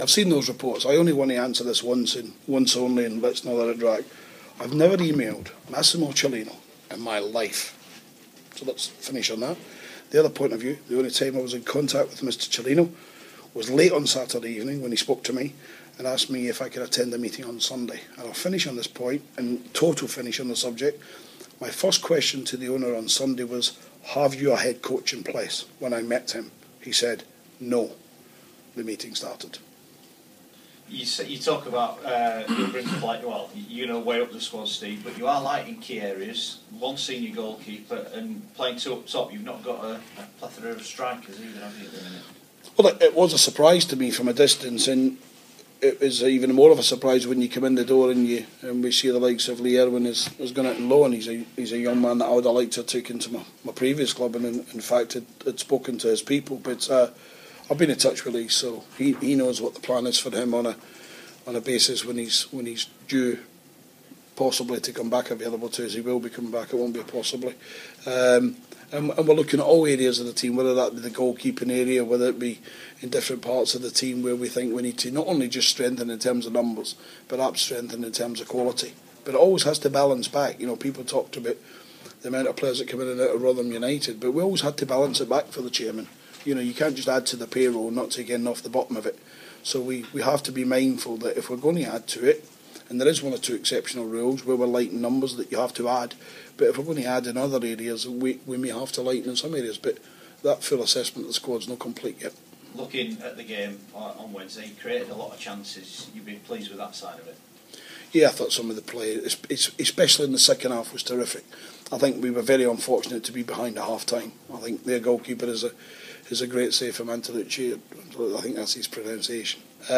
Facing his second game in charge and also his second away game in a row, Steve Evans takes Leeds United to the Macron stadium as they face bottom of the league Bolton Wanderers. Here's the gaffer chatting